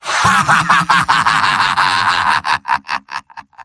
Robot-filtered lines from MvM. This is an audio clip from the game Team Fortress 2 .
{{AudioTF2}} Category:Medic Robot audio responses You cannot overwrite this file.
Medic_mvm_laughlong01.mp3